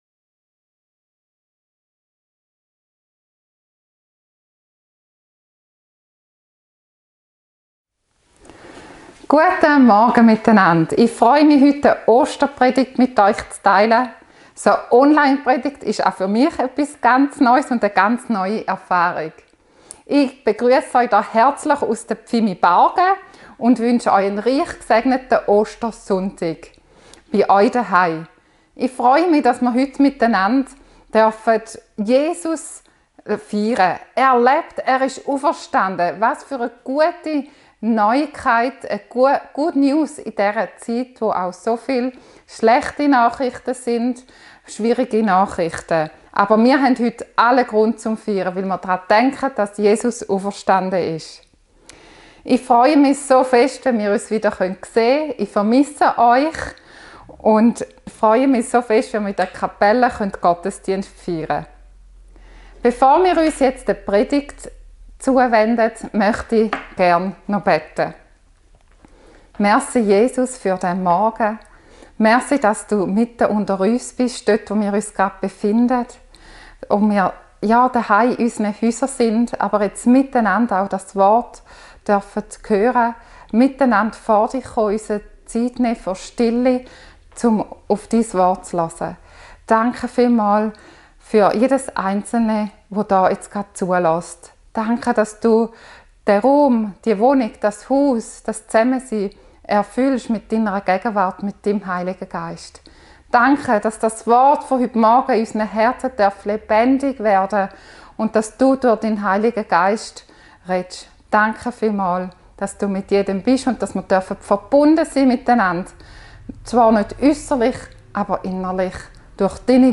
Dienstart: Gottesdienst